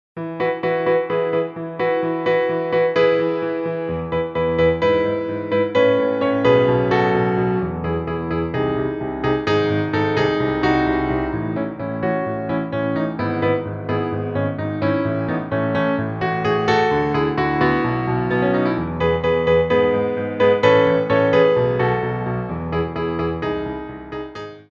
Piano Arrangements
Glissés
4/4 (16x8)